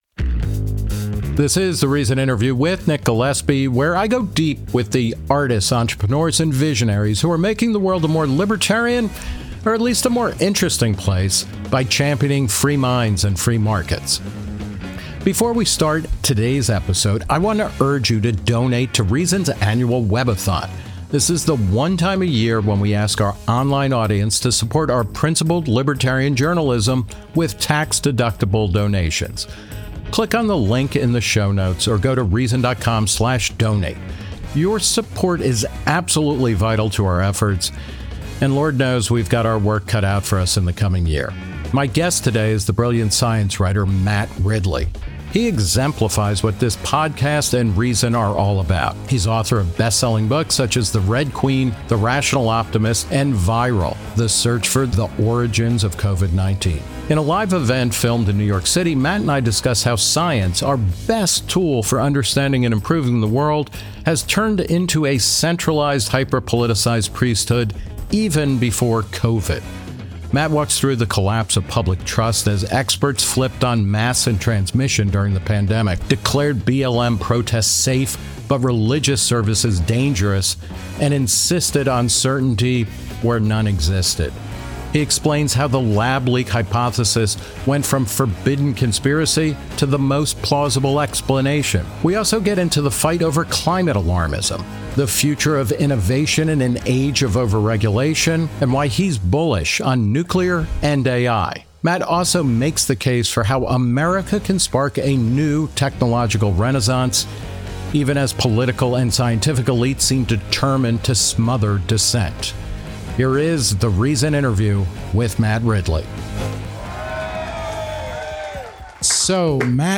The Reason Interview With Nick Gillespie
At a live event filmed in New York City, Ridley tells Nick Gillespie that political and cultural elites had already turned science, our best tool for understanding and improving the world, into a centralized, hyperpoliticized priesthood even before COVID.